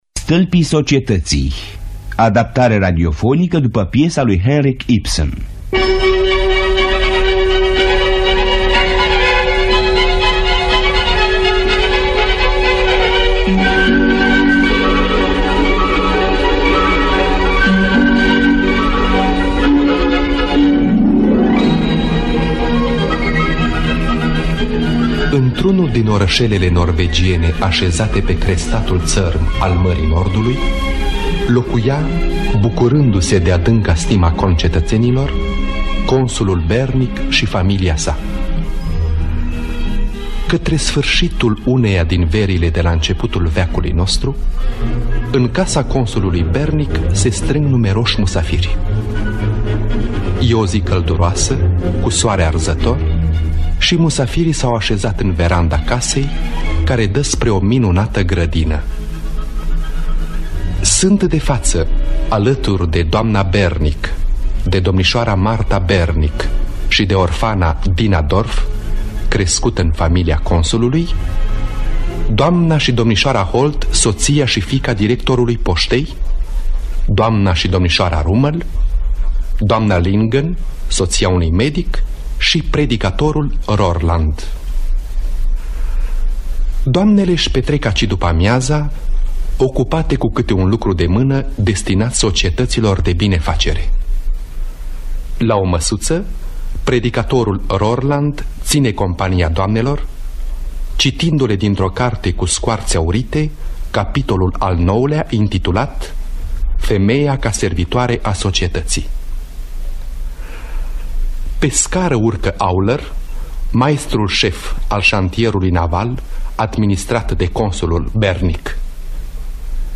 Stâlpii societăţii de Henric Ibsen – Teatru Radiofonic Online